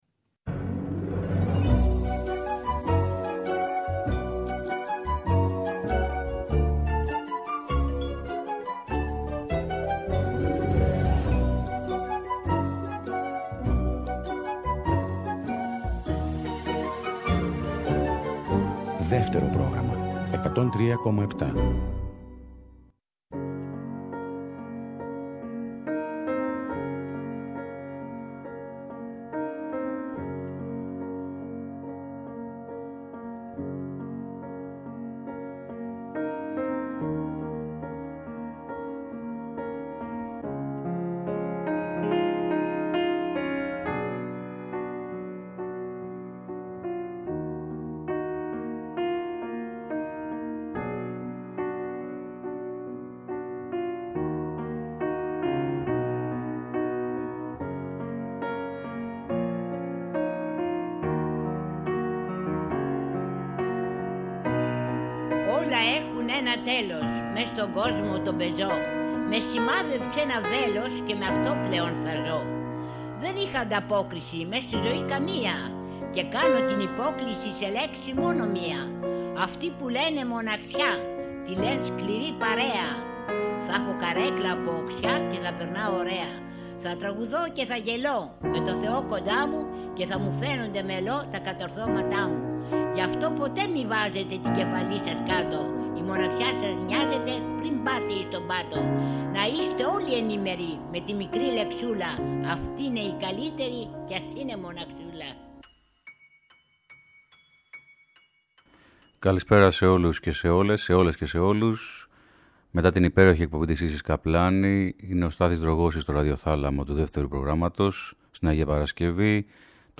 Θα παίξει ασυνήθιστα τραγούδια καινούρια και παλιά και θα διαβάσει ποιήματα του Γιώργου Μαρκόπουλου. Μια εκπομπή για να αποχαιρετίσουμε σιγά σιγά το καλοκαίρι.